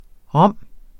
Udtale [ ˈʁʌmˀ ]